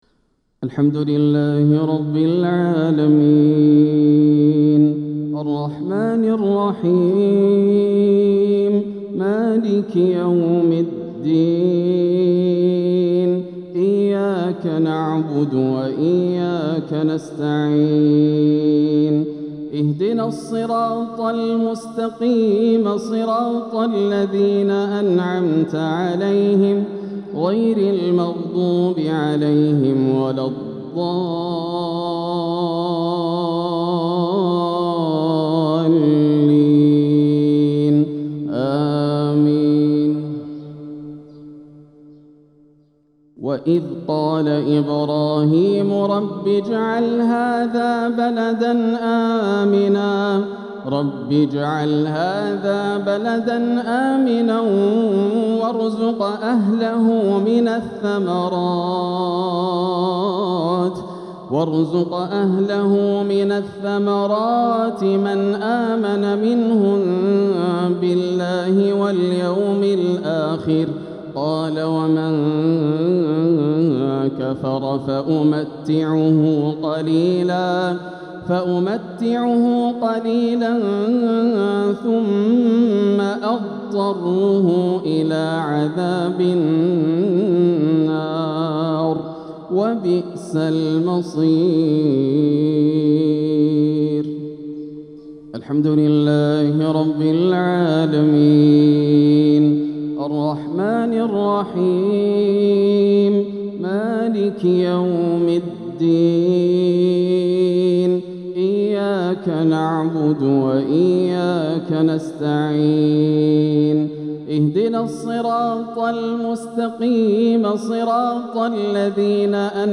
صلاة الجمعة 4 ربيع الآخر 1447هـ | من سورتي البقرة وإبراهيم > عام 1447 > الفروض - تلاوات ياسر الدوسري